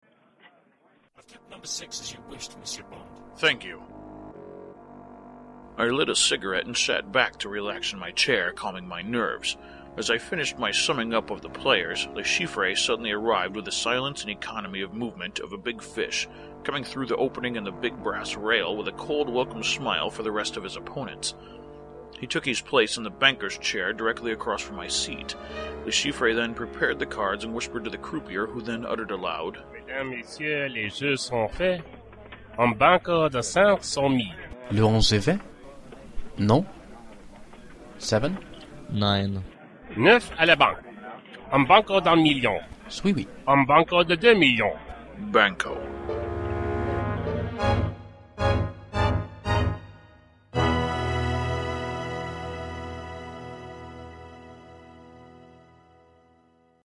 Involvment: Acting (Hussier)